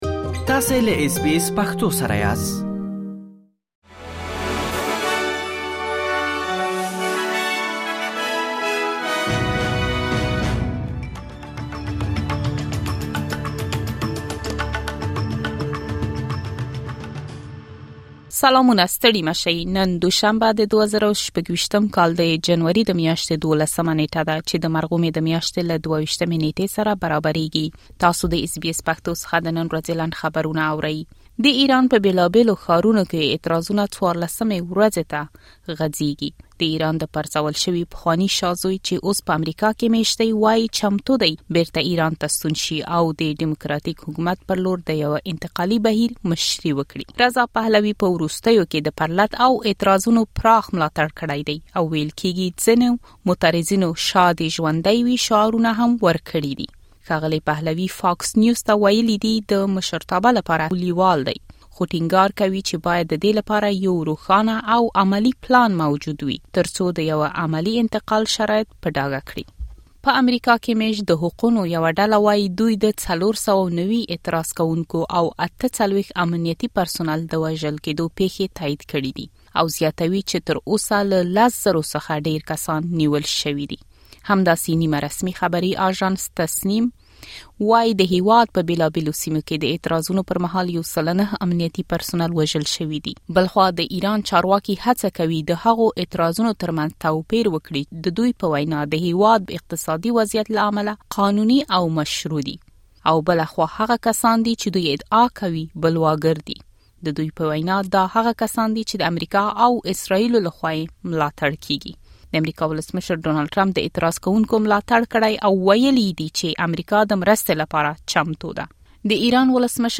د اس بي اس پښتو د نن ورځې لنډ خبرونه |۱۲ جنوري ۲۰۲۶